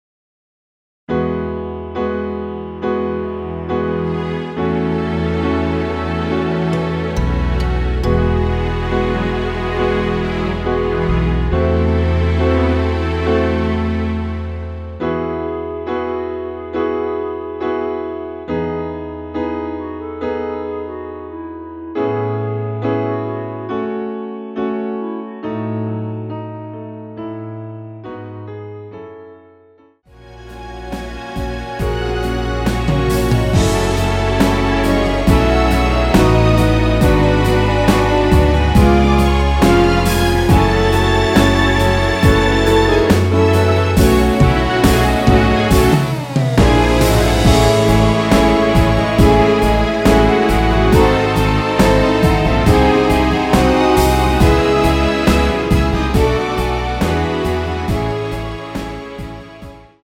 원키 멜로디 포함된 MR입니다.
앞부분30초, 뒷부분30초씩 편집해서 올려 드리고 있습니다.
중간에 음이 끈어지고 다시 나오는 이유는